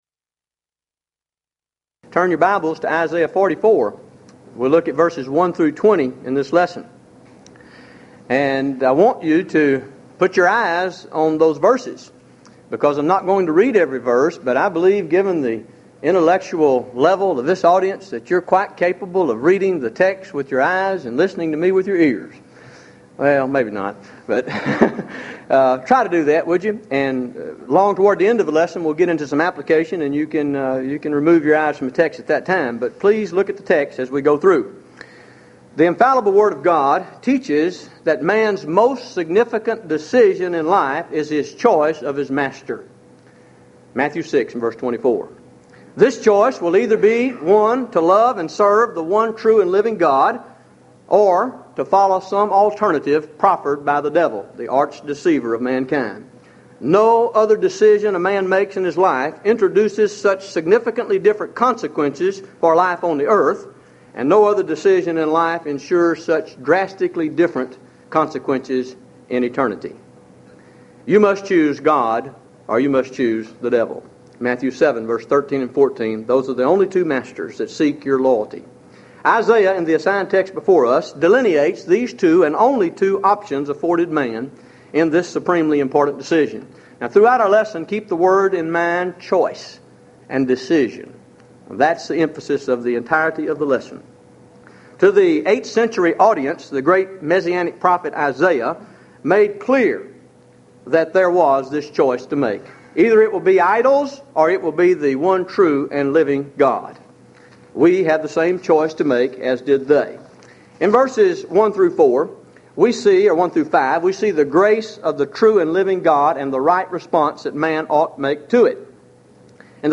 Event: 1996 HCB Lectures
lecture